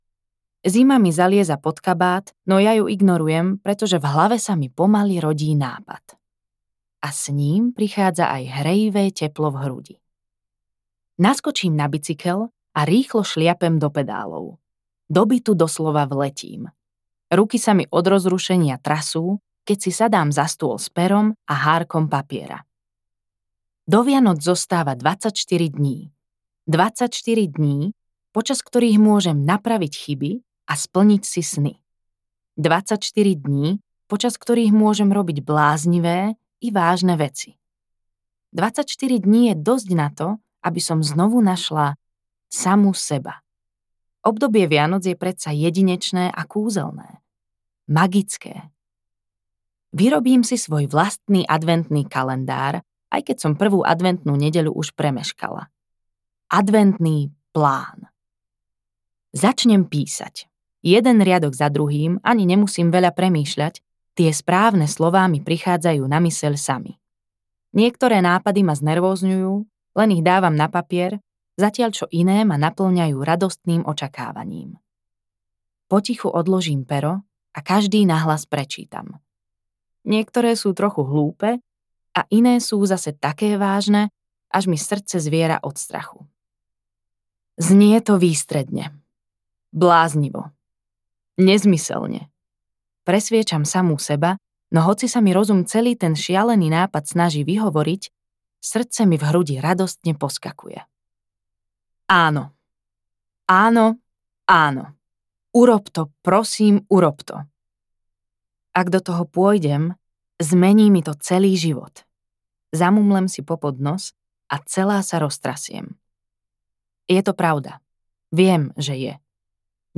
24 dní do Vianoc audiokniha
Ukázka z knihy